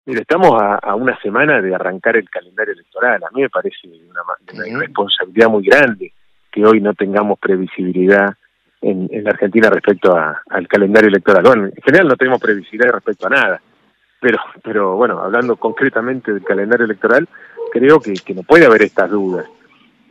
el ex ministro del Interior, Rogelio Frigerio, habló de la actualidad provincial:
Declaraciones-de-Rogelio-Frigerio-3.mp3